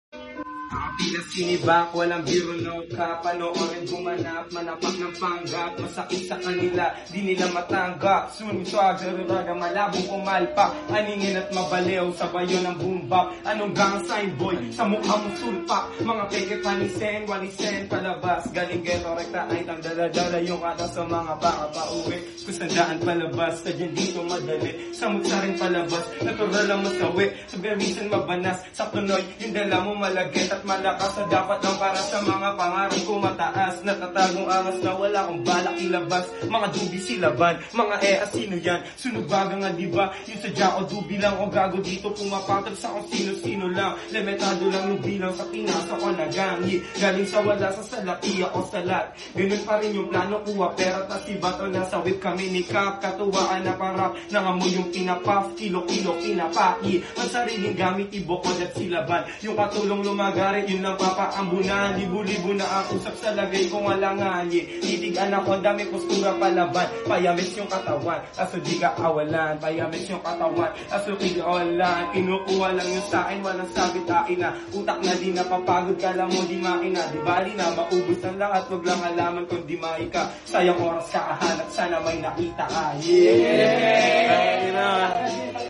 Freestyle Rap